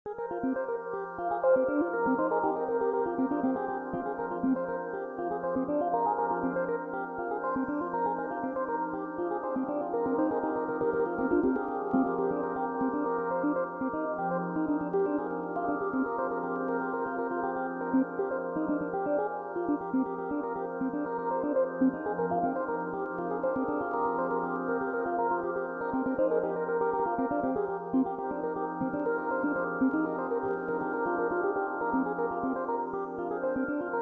minimal layerd loop